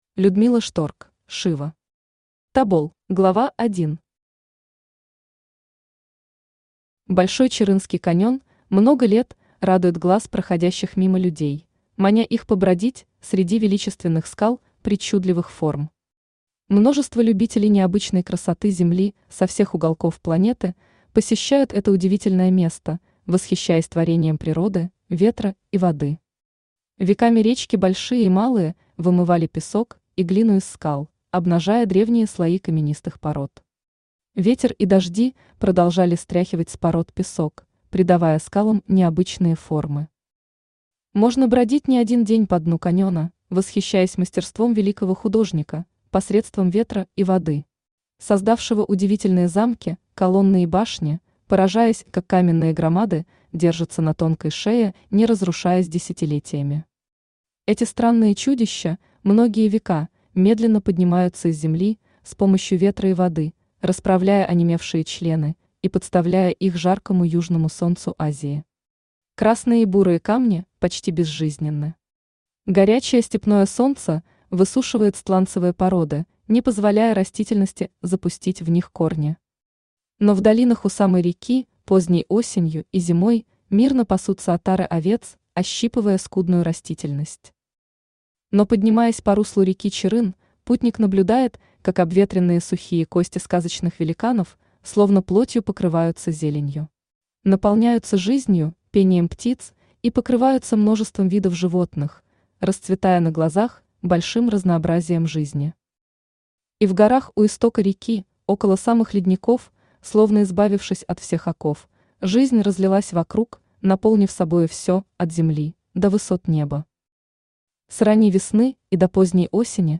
Аудиокнига Тобол | Библиотека аудиокниг
Aудиокнига Тобол Автор Людмила Шторк – Шива Читает аудиокнигу Авточтец ЛитРес.